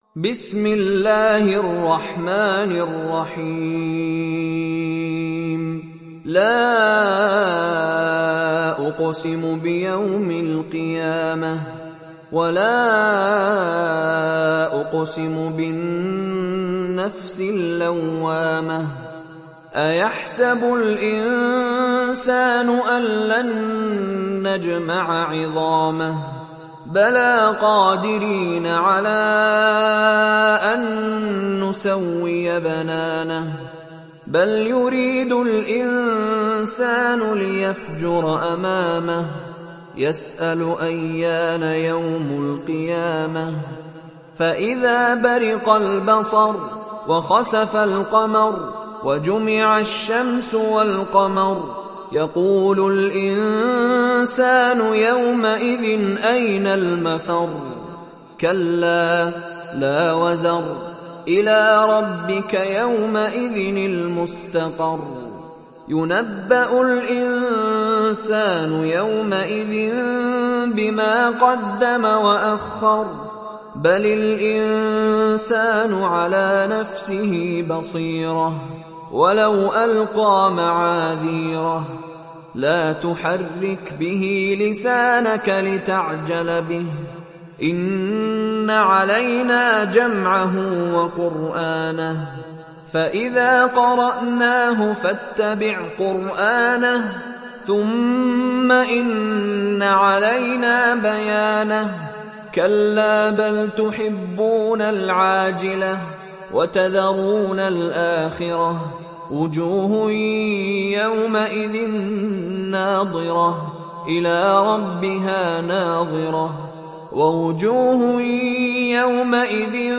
دانلود سوره القيامه mp3 مشاري راشد العفاسي روایت حفص از عاصم, قرآن را دانلود کنید و گوش کن mp3 ، لینک مستقیم کامل